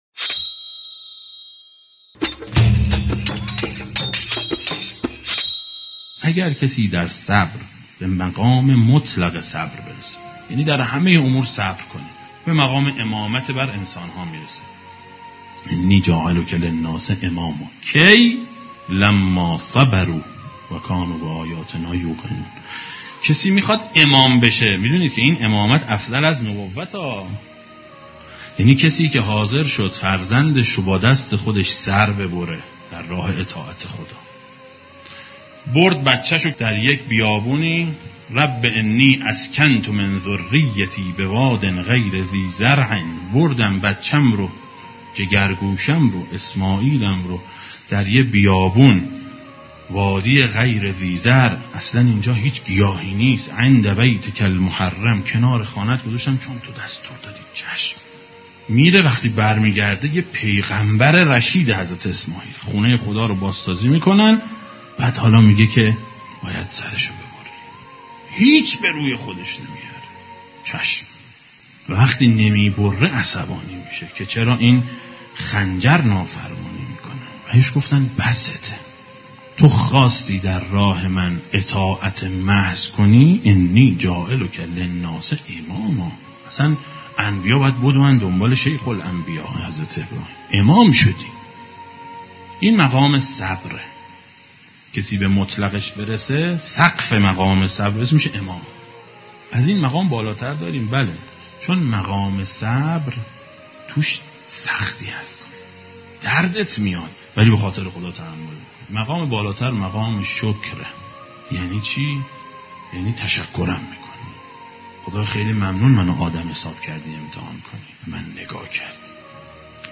روضه شب چهارم محرم سال 1394